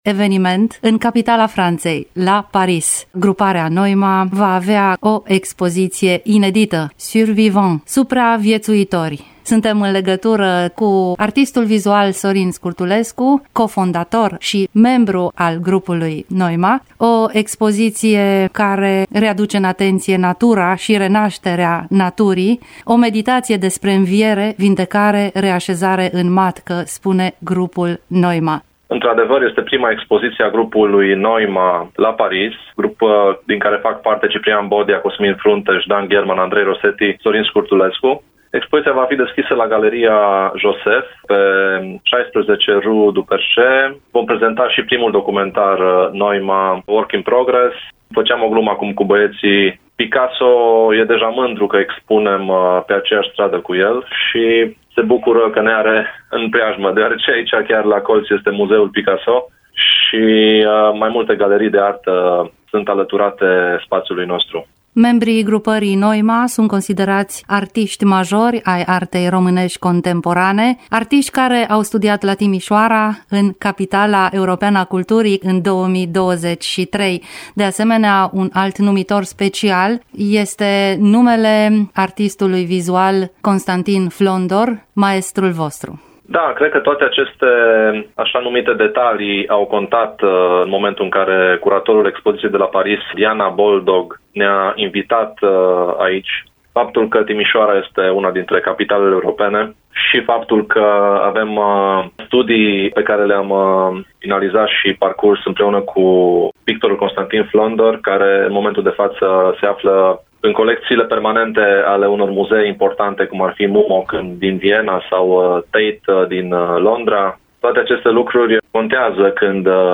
Detalii despre expoziţie în dialogul